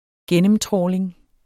Udtale [ -ˌtʁɒˀleŋ ]